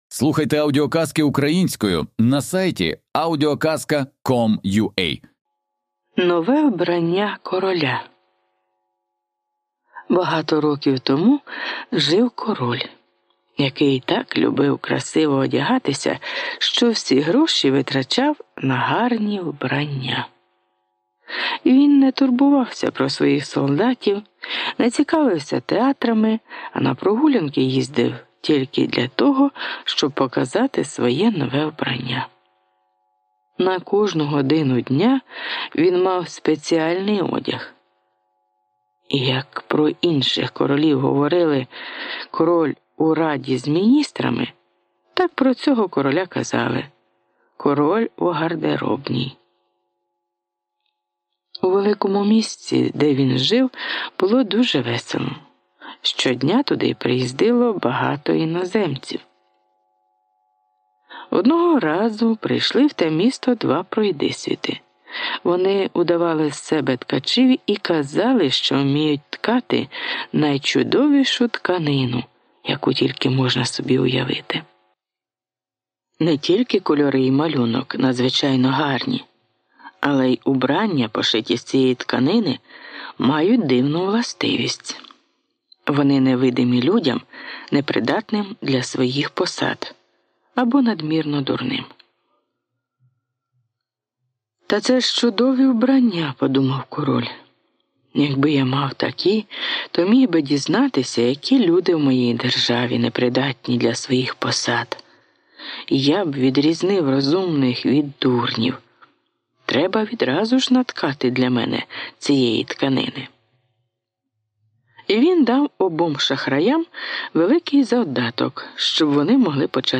Аудіоказка Нове вбрання короля